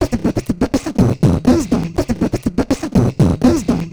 Also below in a faux Ableton-style interface are all the original loops used to create the tracks.
BeatBox.wav